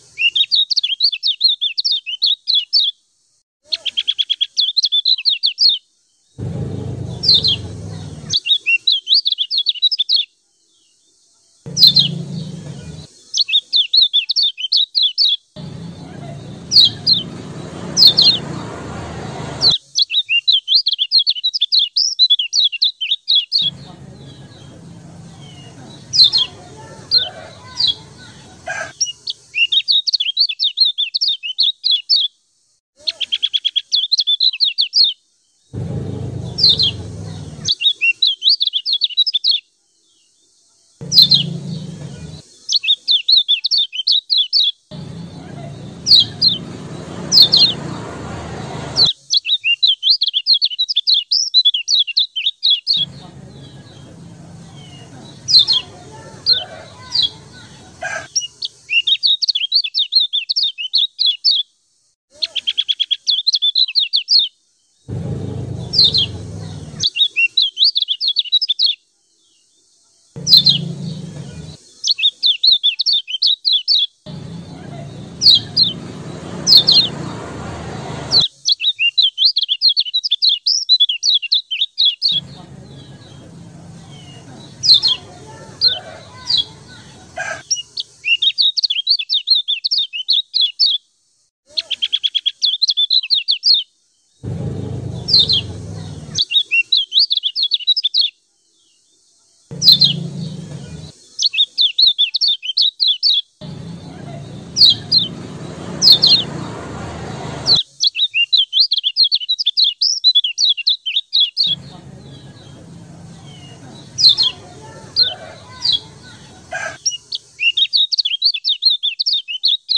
Suara Burung Kecial Kuning Ngejos
Kategori: Suara burung
Wajib coba, ciak ciak joss kecial kuning satu ini, tercepat bikin kecial emosi ikut gacor ngejos!
suara-burung-kecial-kuning-ngejos-id-www_tiengdong_com.mp3